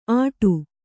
♪ āṭu